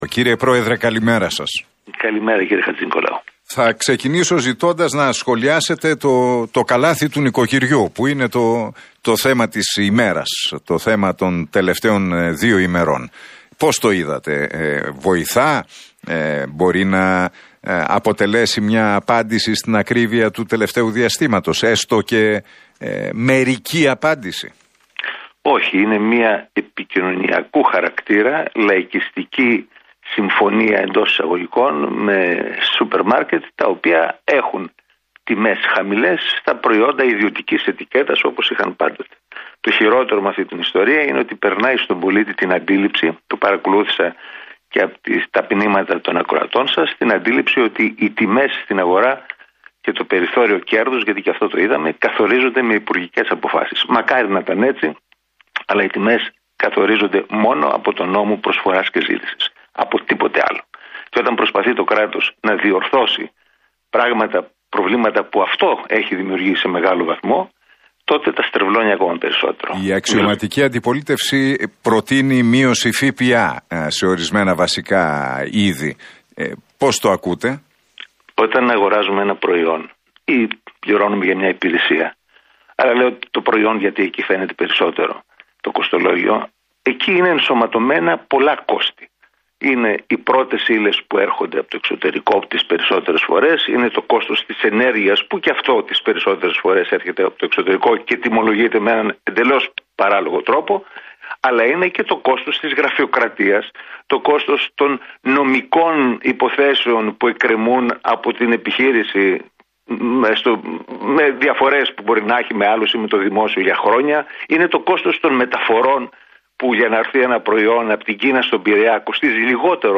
Στον Realfm 97,8 και τον Νίκο Χατζηνικολάου μίλησε ο πρόεδρος της «Εθνικής Δημιουργίας», Θάνος Τζήμερος.